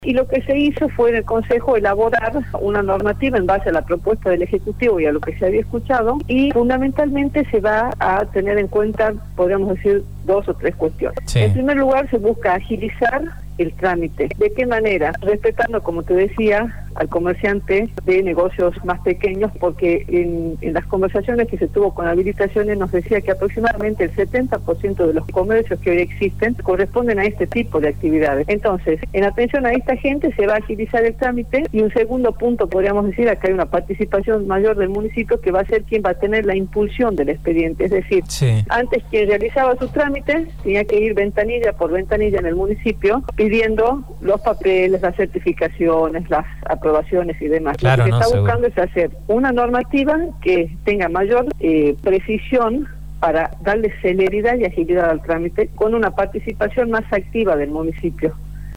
La concejal Socorro Villamayor se refirió a una ordenanza que busca agilizar el tiempo para habilitar pequeños comercios en la ciudad, dijo que se está buscando hacer una normativa que tenga mayor precisión para darle celeridad y agilidad al trámite y lograr una mayor participación del municipio.